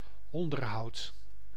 Ääntäminen
IPA: [ɔn.dǝɾ.ɦʌud]